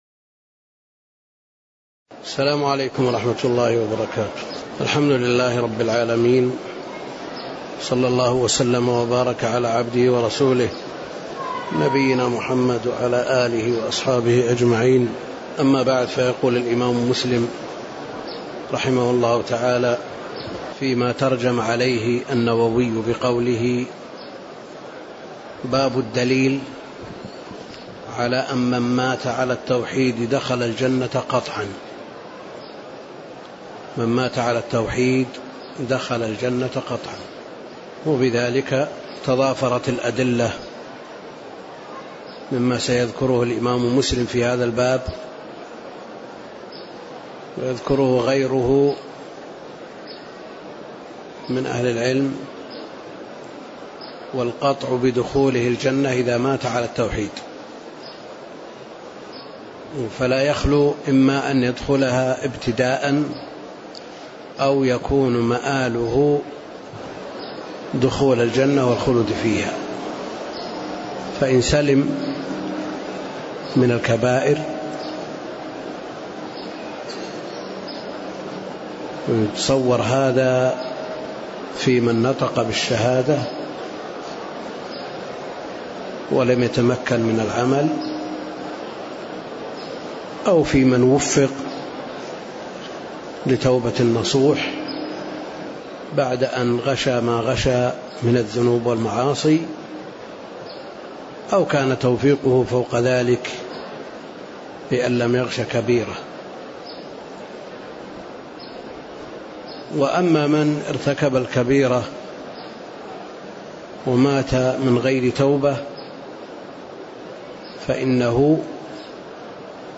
تاريخ النشر ٢٩ جمادى الآخرة ١٤٣٤ المكان: المسجد النبوي الشيخ: فضيلة الشيخ د. عبدالكريم الخضير فضيلة الشيخ د. عبدالكريم الخضير باب الدليل على من مات على التوحيد دخل الجنة قطعًا (09)